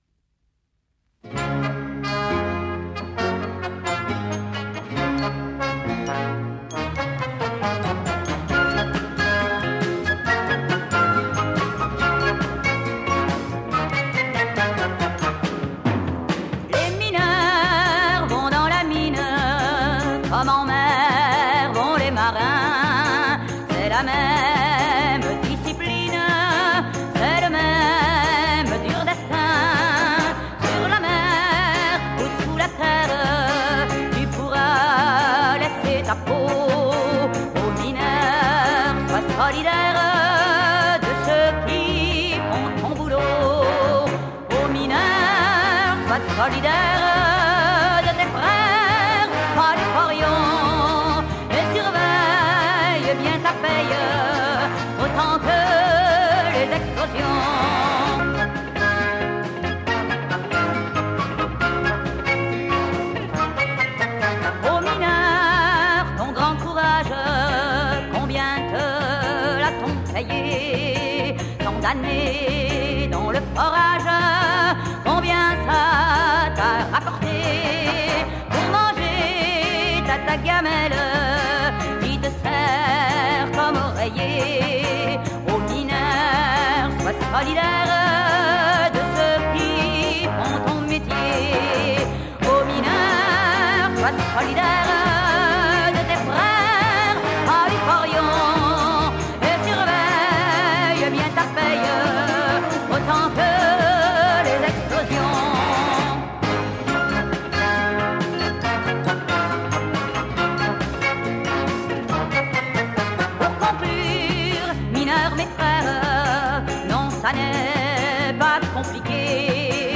Enregistré au Théâtre Gérard Philipe de St-Denis